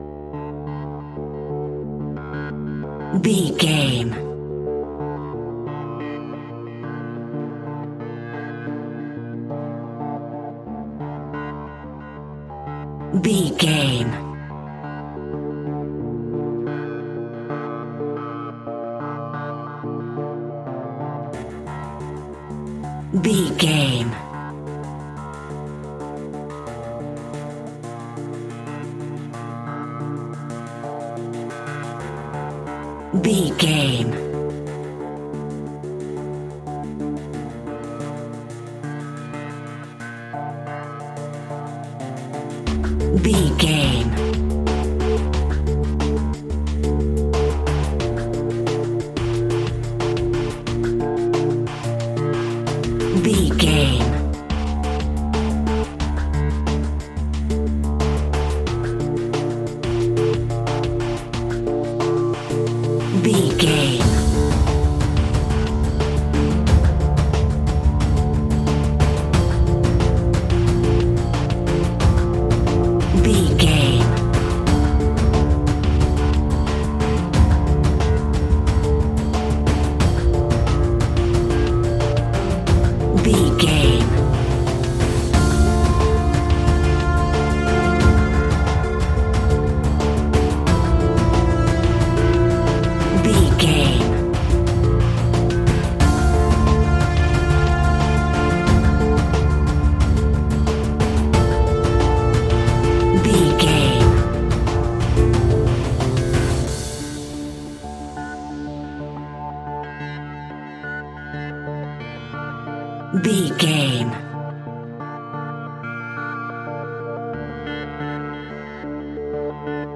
Progressive Arp Synth Stinger.
Aeolian/Minor
ominous
dark
haunting
eerie
synthesiser
drums
percussion
ticking
electronic instrumentals